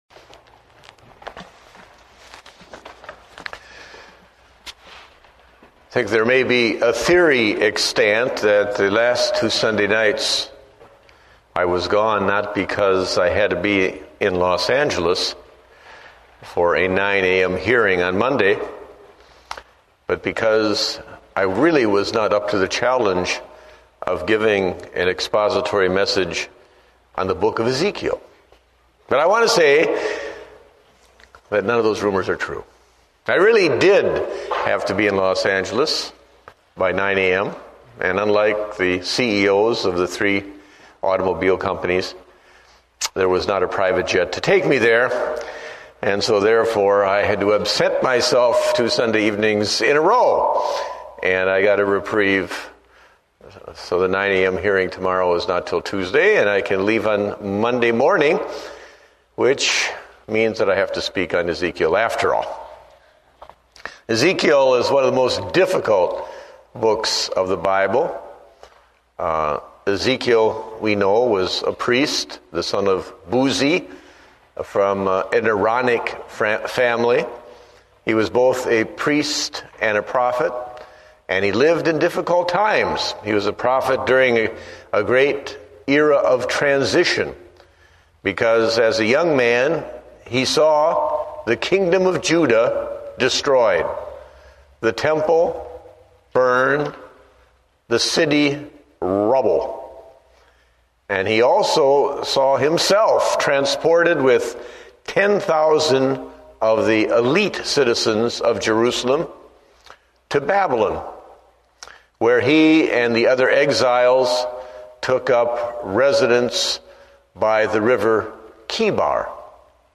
Date: December 7, 2008 (Evening Service)